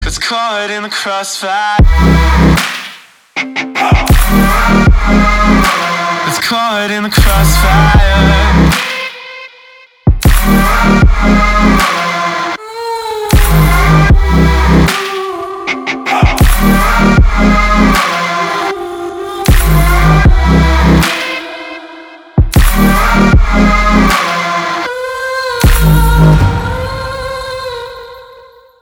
• Качество: 320, Stereo
громкие
красивый мужской голос
Trap
alternative
Атмосферный трэпчик